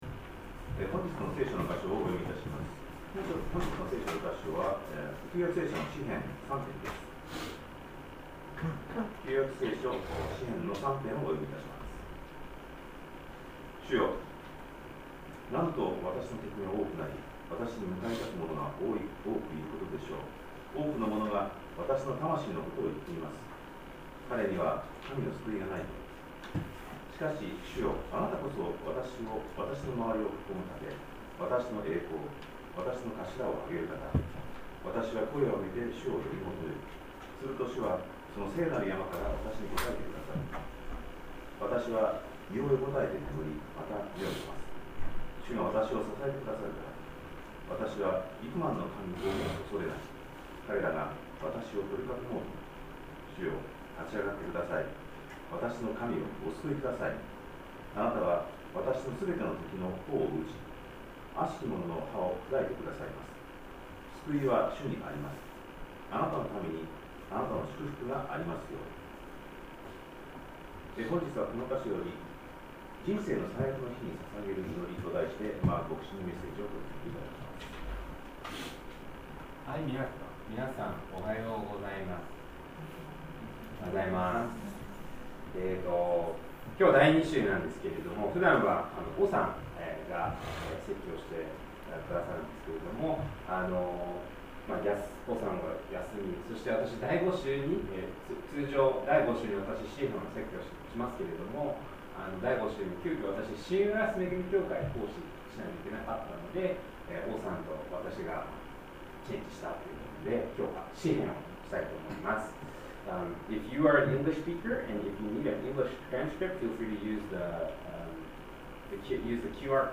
2025年8月10日礼拝 説教 「人生の最悪の日に捧げる祈り」 – 海浜幕張めぐみ教会 – Kaihin Makuhari Grace Church